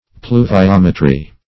Search Result for " pluviometry" : The Collaborative International Dictionary of English v.0.48: Pluviometry \Plu`vi*om"e*try\, n. [L. pluvia rain + -metry.]